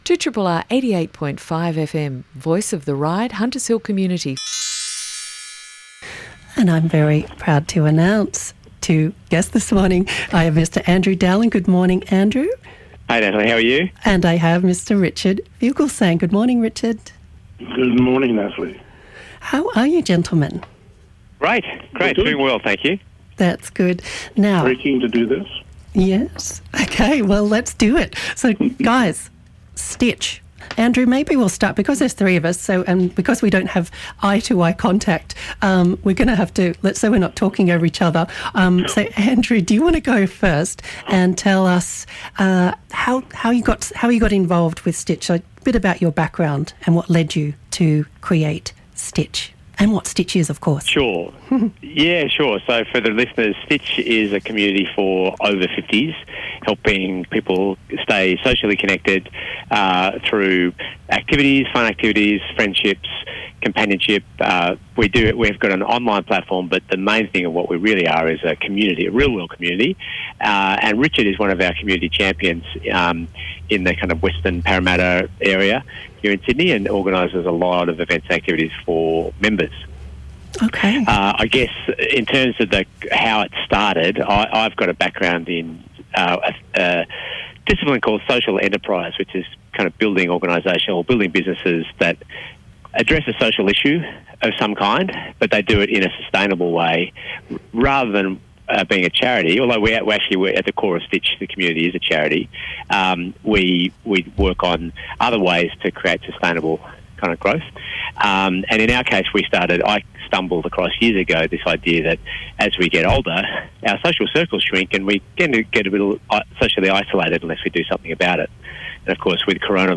2RRR Sydney – Interview